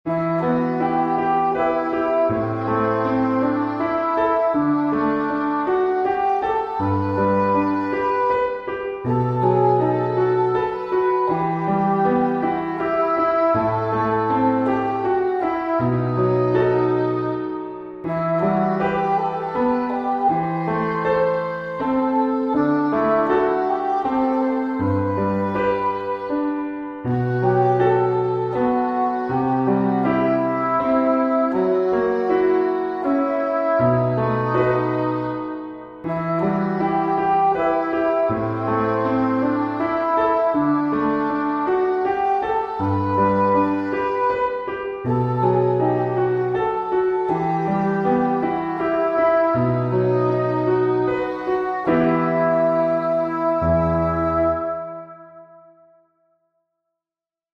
ENTONACIÓN
Entonación 2: Mi menor
Entonación-2.mp3